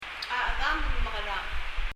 発音
mekeráng　　[mɛkɛraŋ]　　　　do what?